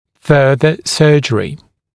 [‘fɜːðə ‘sɜːʤ(ə)rɪ][‘фё:зэ ‘сё:дж(э)ри]дальнейшее хирургическое лечение